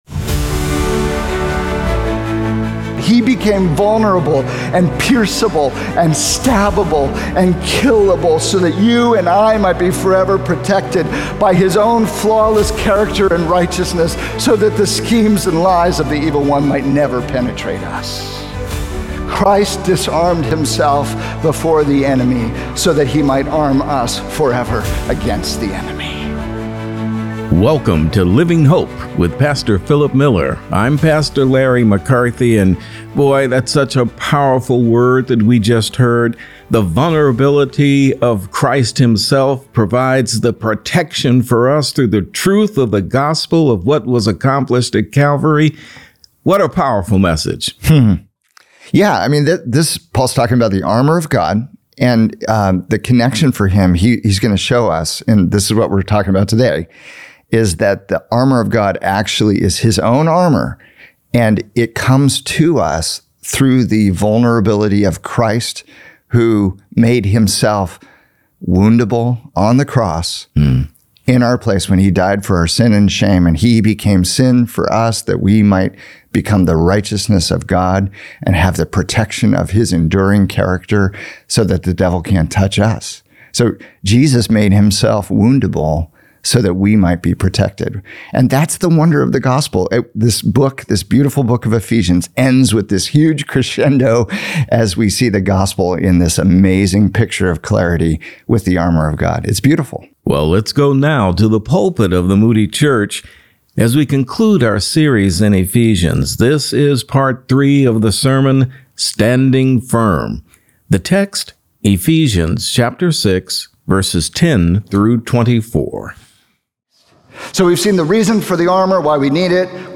Armed for Victory In Your New Invincible Identity | Radio Programs | Living Hope | Moody Church Media